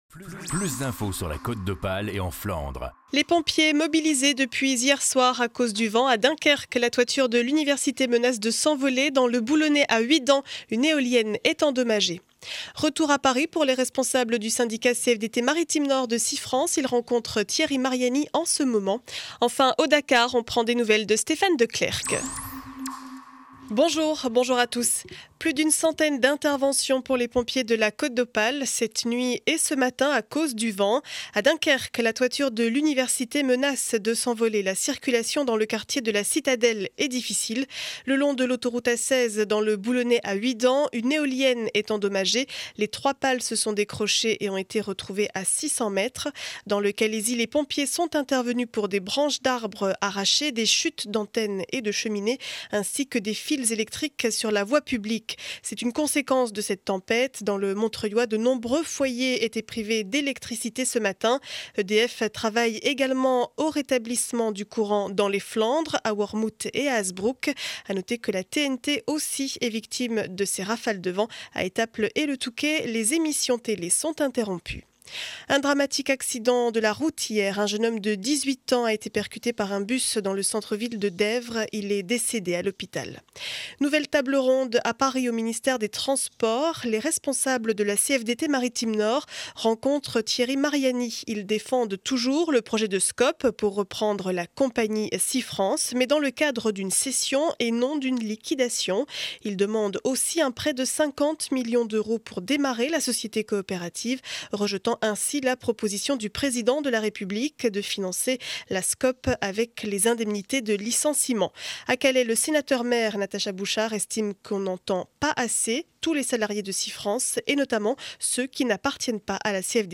Journal du jeudi 05 janvier 2012.